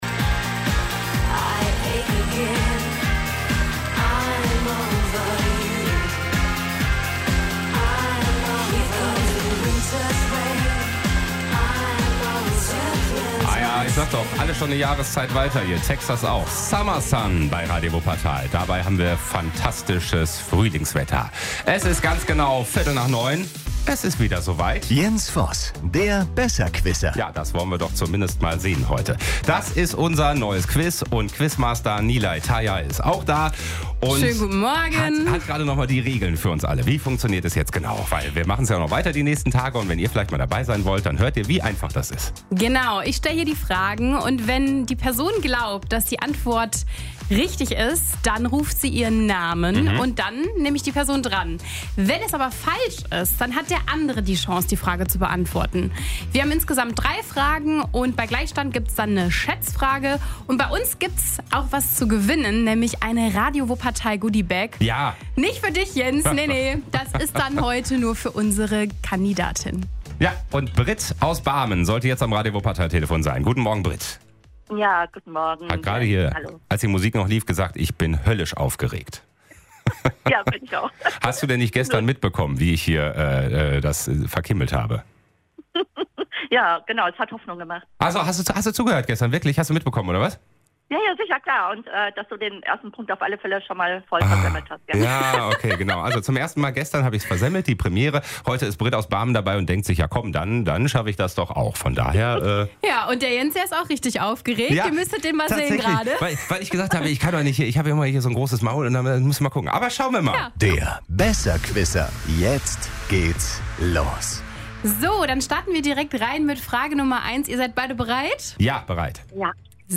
Wer die Antwort weiß, ruft schnell seinen Namen. Wer zuerst richtig antwortet, holt den Punkt.